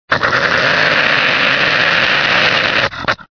Звуки барсука